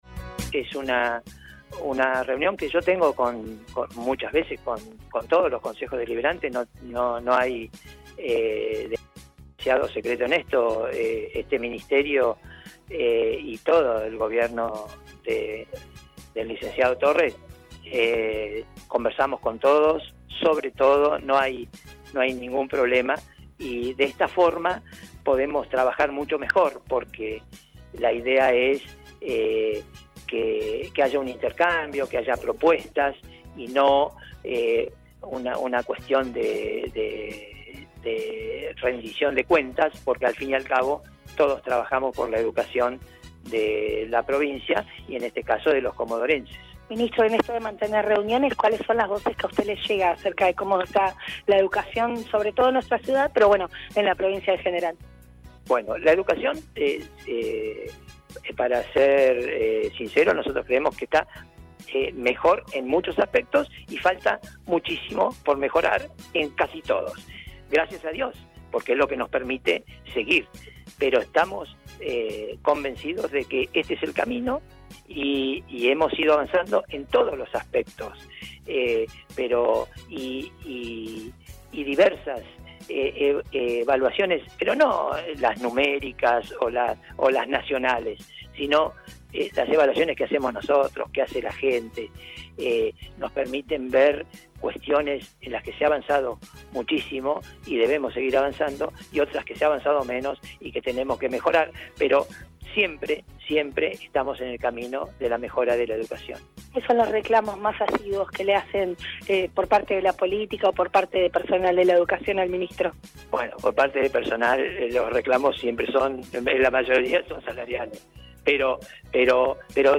El Ministro de Educación de la provincia, José Luis Punta, estuvo en el Concejo Deliberante respondiendo consultas a los concejales: